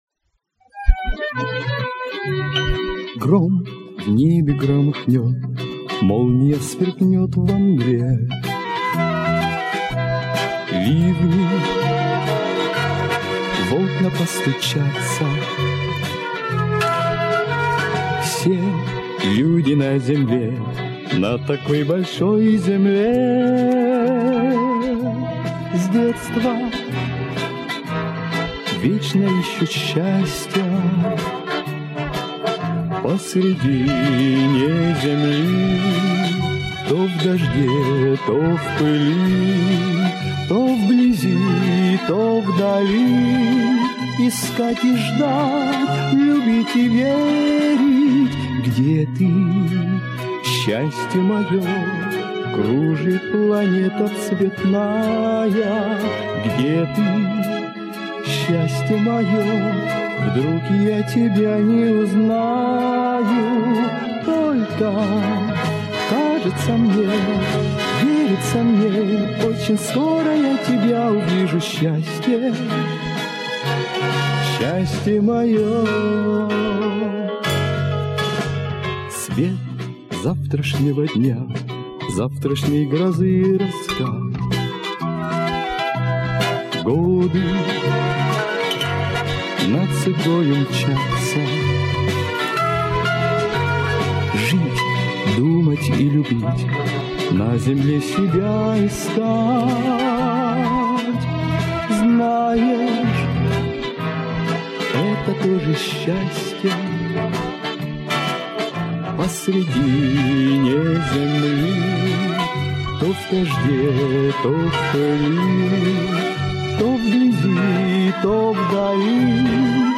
Нашлась эта песенка, но только в его сольном исполнении.